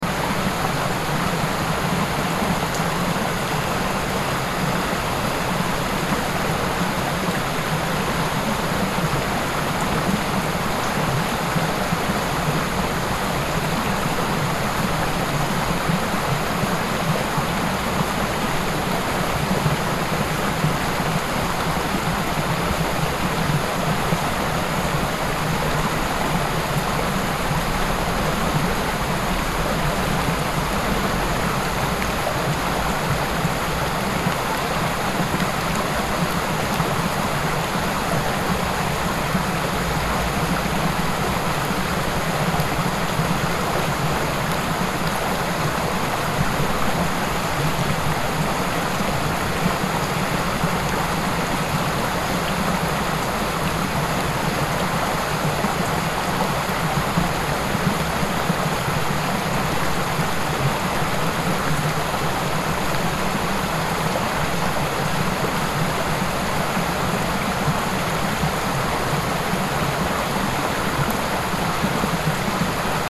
Forest River Flow Sound Effect
This loopable forest river flow sound effect captures the gentle, natural sound of water moving through a quiet woodland stream. Soft currents, bubbling eddies, and subtle forest ambience blend seamlessly to create a peaceful, continuous, and immersive atmosphere.
Water sounds.
Forest-river-flow-sound-effect.mp3